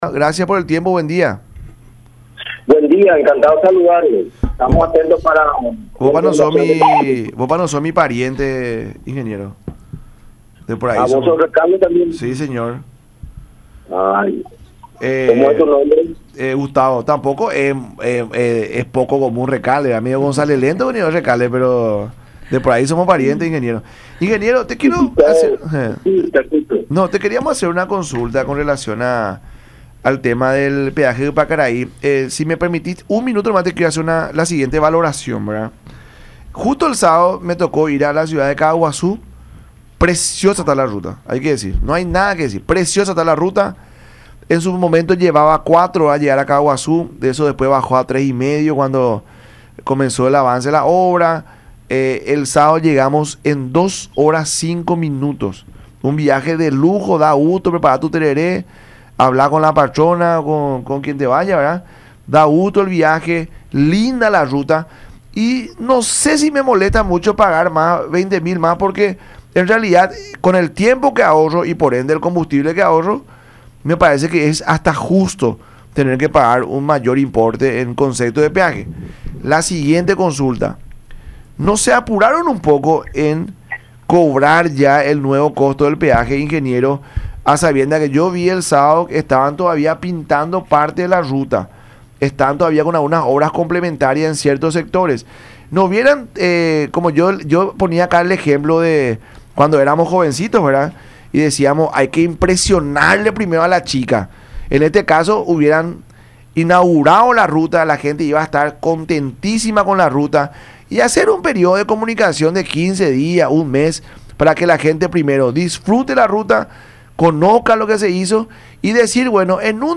en entrevista con el programa “La Mañana De Unión” por Radio La Unión y Unión Tv.